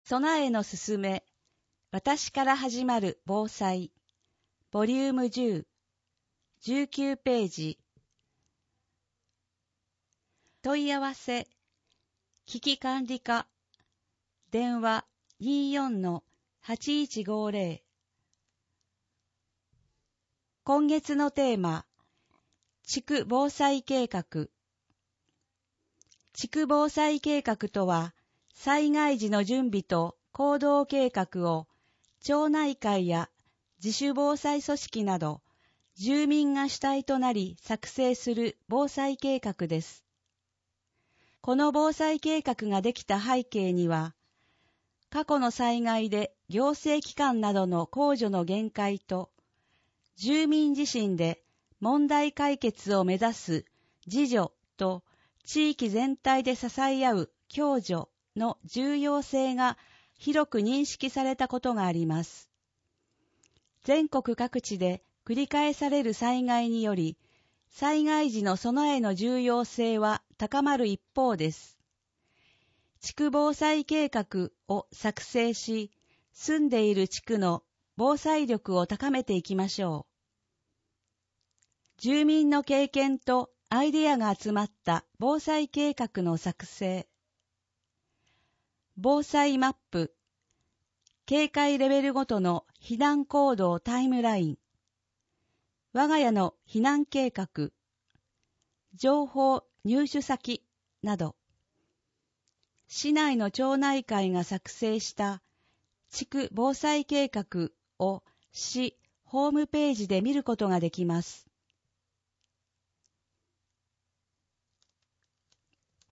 広報こまつの音訳は音訳ボランティアグループ「陽だまり会」の皆さんの協力で行っています。